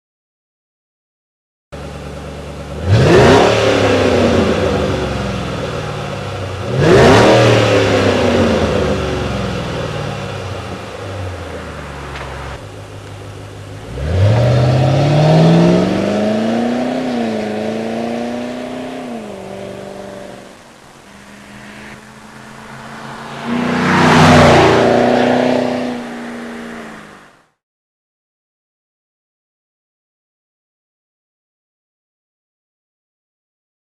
• Non-Reversible Custom Tuned Flow Design